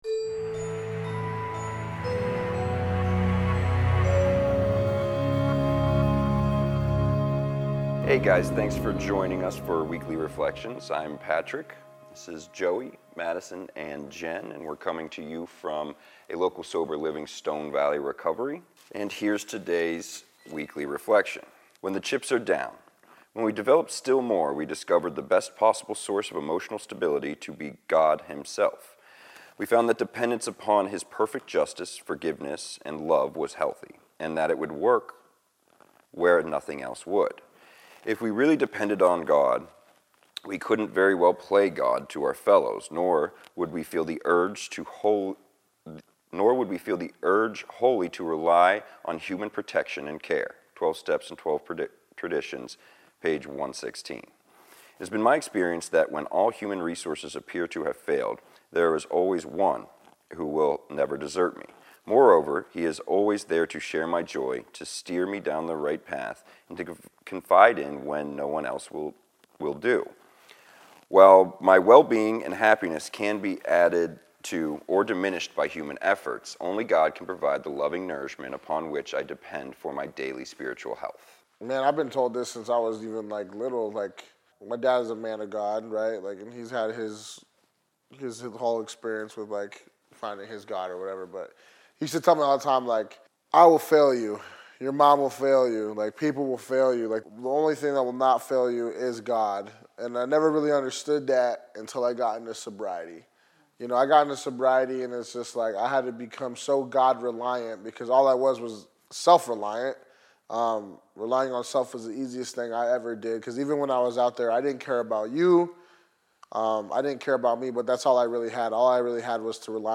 share their experiences about creating a better quality of life in recovery. With our relationship with our higher power being the most important priority, we find deliverance from the grip of addiction. Special Thanks to Stone Valley Recovery for providing the location for Recovery Reflections.